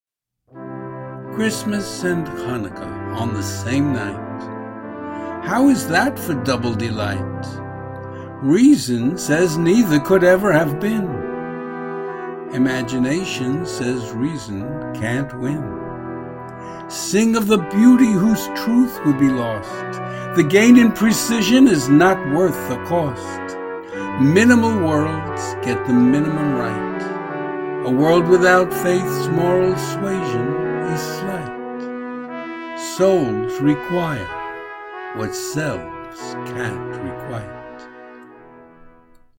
Audio and Video Music:
Holiday Brass Ensemble